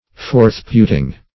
Meaning of forthputing. forthputing synonyms, pronunciation, spelling and more from Free Dictionary.
Forthputing \Forth"put`ing\